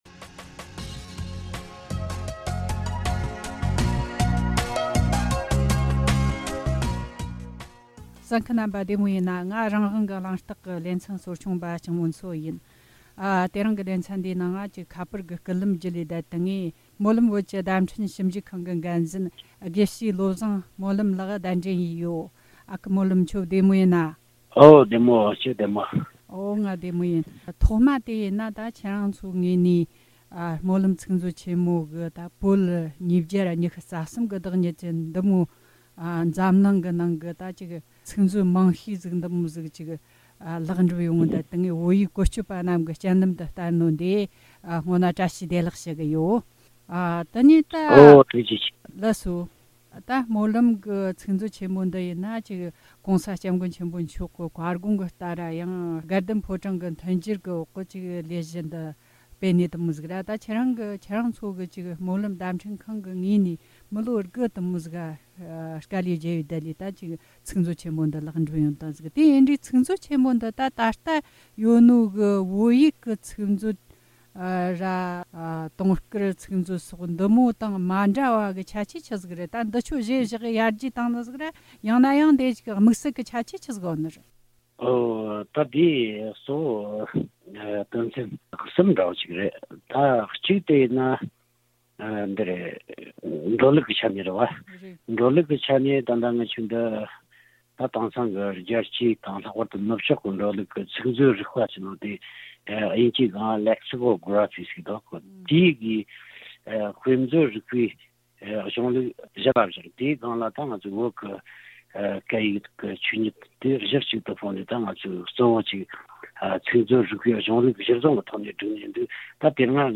བཀའ་འདྲི་ཞུས་པ།